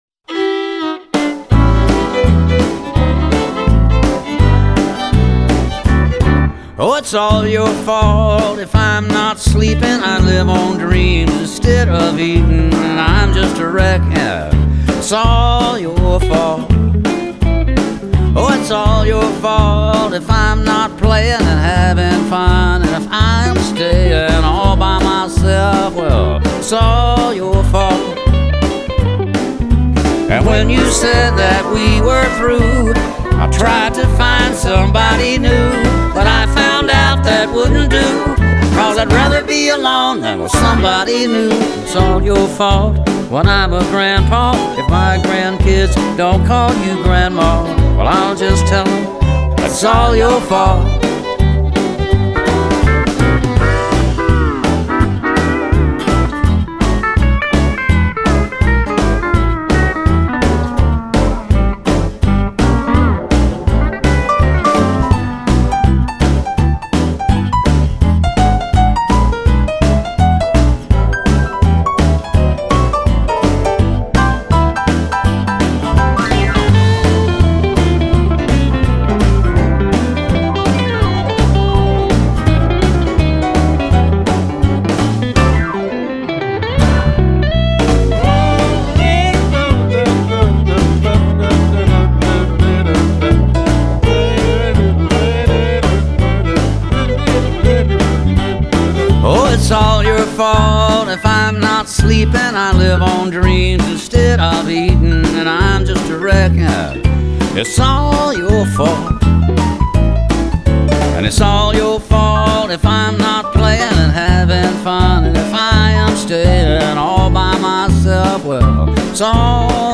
乡村歌曲